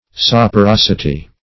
Search Result for " saporosity" : The Collaborative International Dictionary of English v.0.48: Saporosity \Sap`o*ros"i*ty\, n. The quality of a body by which it excites the sensation of taste.